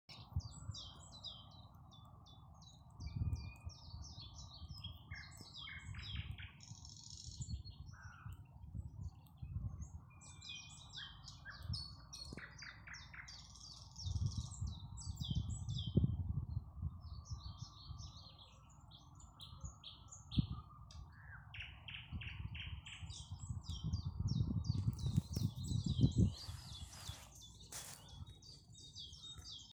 Thrush Nightingale, Luscinia luscinia
StatusSinging male in breeding season
NotesDzied biezā krūmājā, blakus dīķis